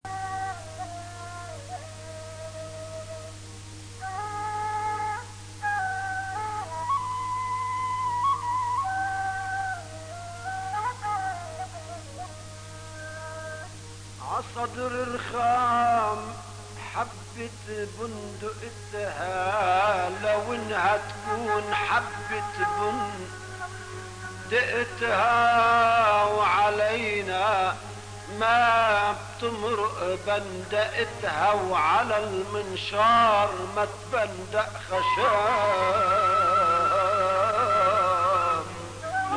ataba
العتابا